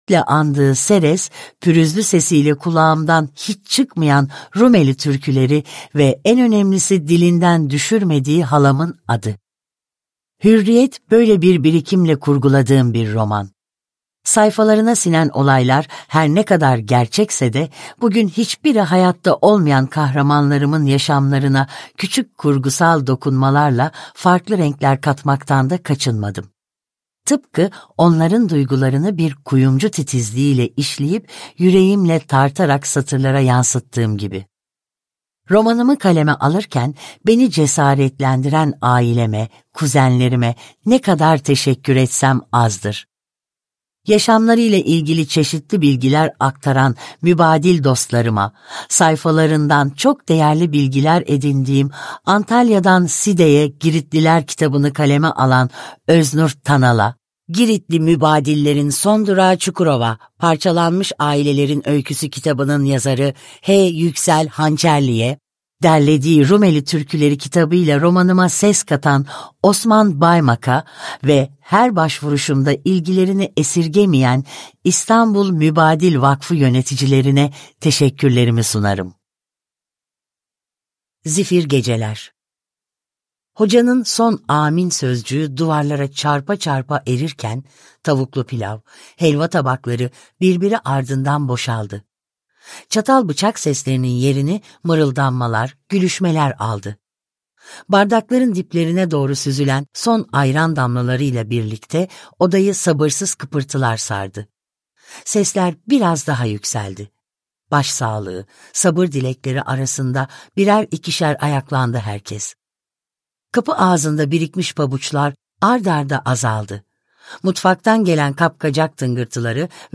Hürriyet - Bir Sevda Masalı - Seslenen Kitap